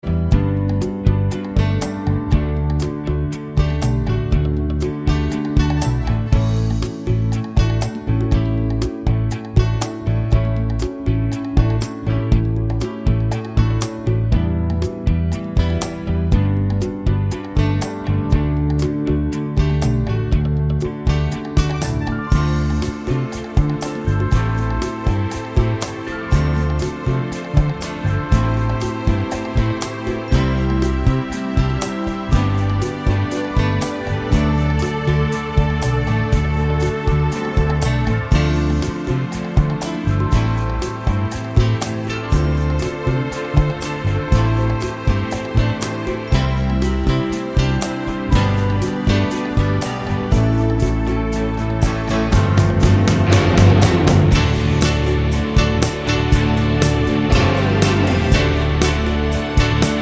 D Major Minus Clarinet Pop (1970s) 4:15 Buy £1.50